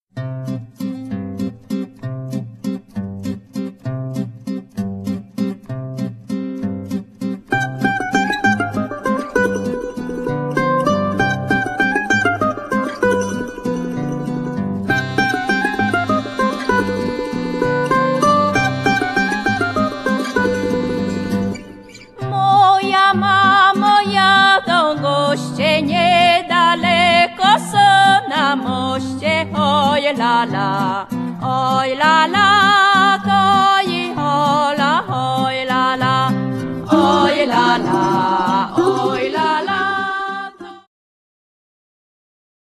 akordeon / accordion
klarnet / clarinet
mandolina / mandoline
saksofon barytonowy / baritone saxophone
kontrabas / double bass
cymbały huculskie / hutsul dulcimer
lira korbowa / hurdy-gurdy
dudy / bagpipe